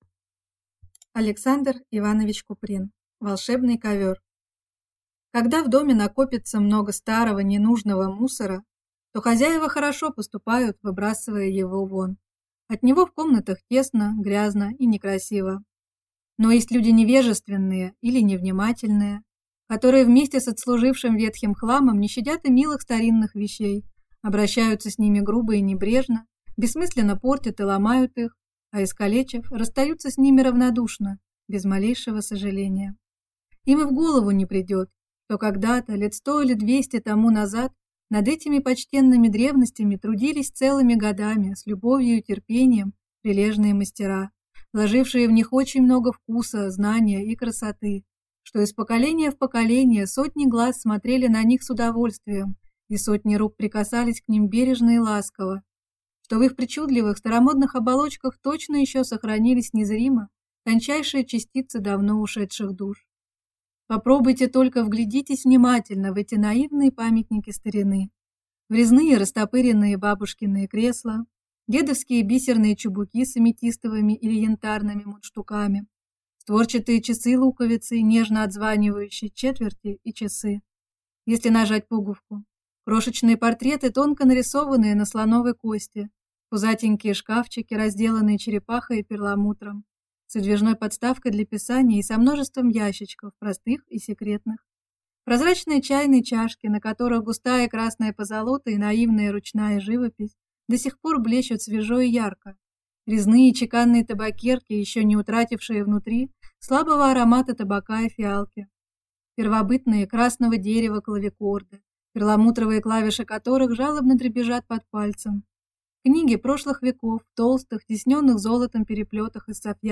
Аудиокнига Волшебный ковер | Библиотека аудиокниг
Прослушать и бесплатно скачать фрагмент аудиокниги